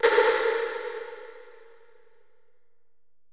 808LP37CRM.wav